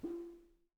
timpsnare_ppp.wav